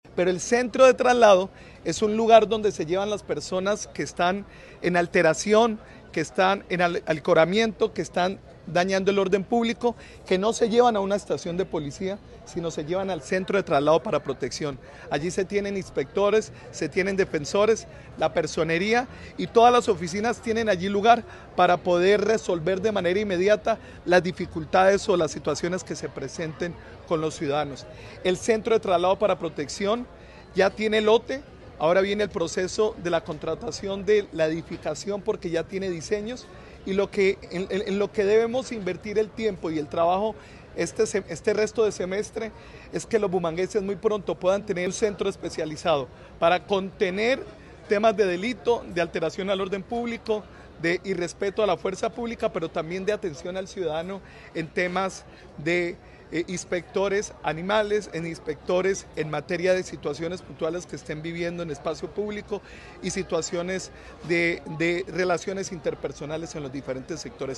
Jaime Beltrán, alcalde de Bucaramanga
Intervención del alcalde de Bucaramanga sobre el CTP